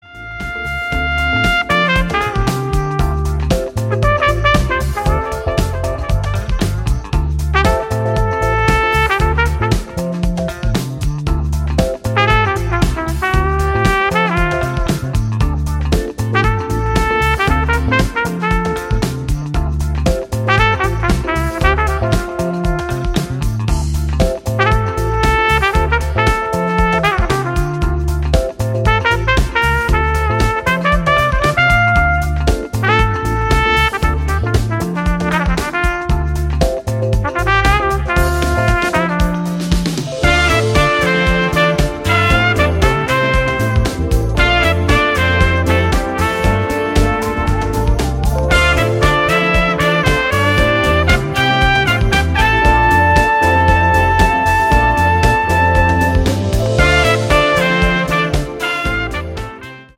funky Smooth Jazz